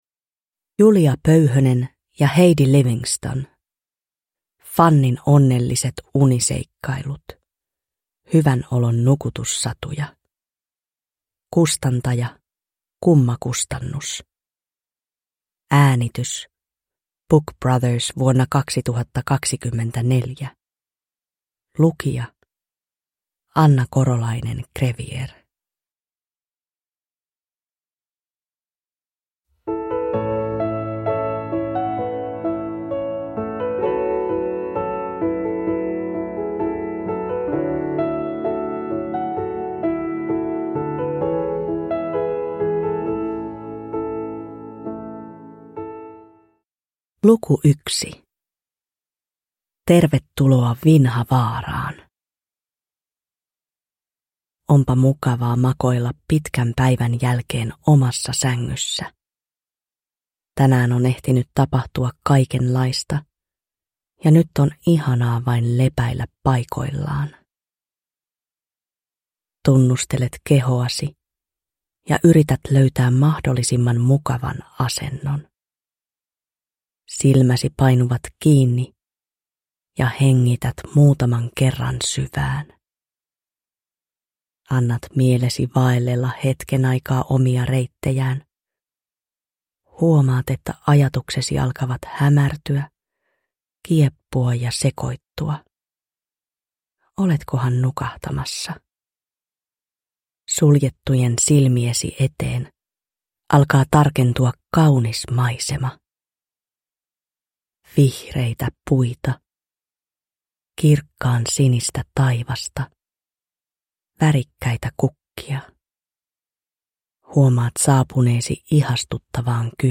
Jokainen nukahtamissatu päättyy uneen johdattavaan musiikkiin.
• Ljudbok